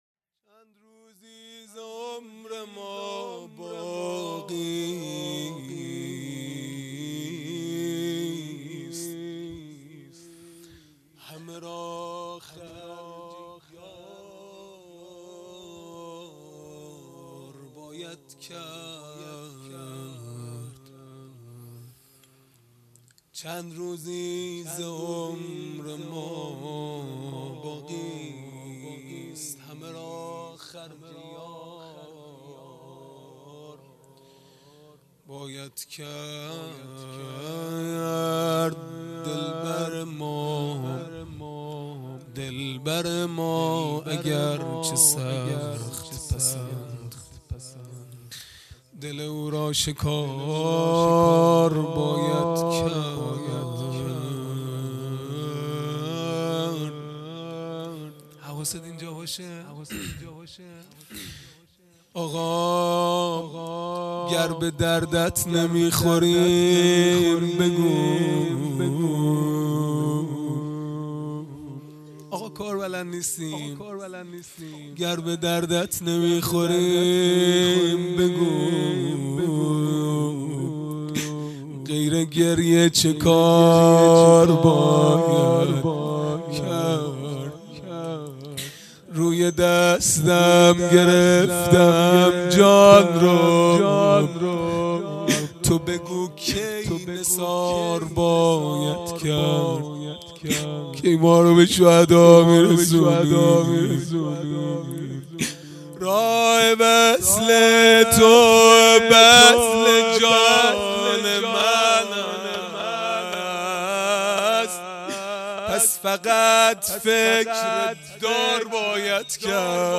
مناجات پایانی | چند روزی ز عمر ما باقیست
جلسه هفتگی | جلسه هفتگی هیات به مناسبت شهادت حضرت حمزه(ع) | ۲۲ خرداد ۱۳۹۹